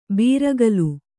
♪ bīragalu